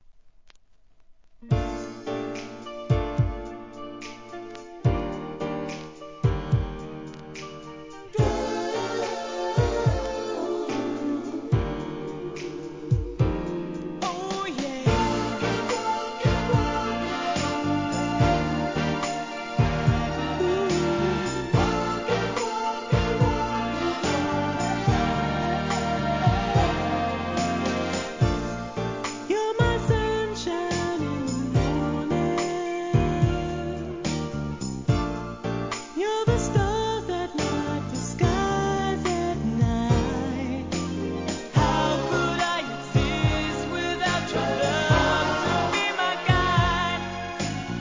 HIP HOP/R&B
透き通るようなコーラスWORKで聴かせます♪